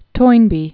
(toinbē), Arnold Joseph 1889-1975.